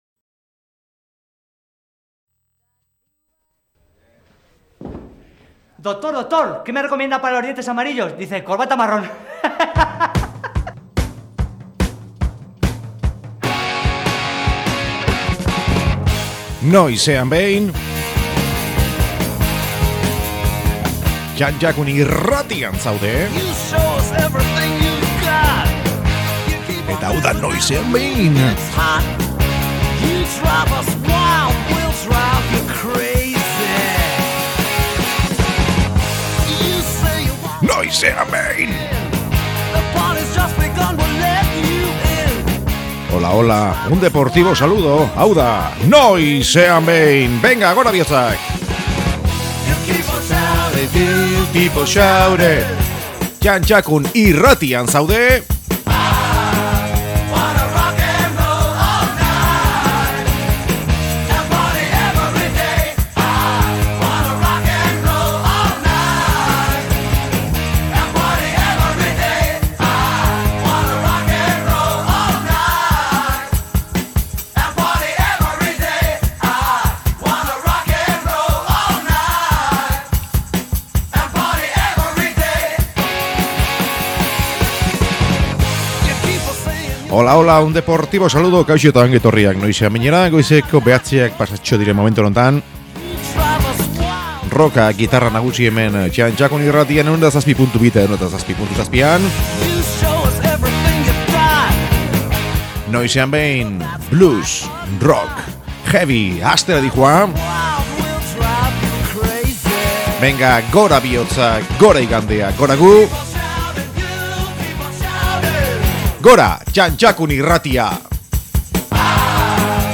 Gaurkoan programa itzela, blues goxo goxoa, rock pixka bat eta METALLL pixka bat askoz gehiago, gaurkoan gabon ikutu batekin.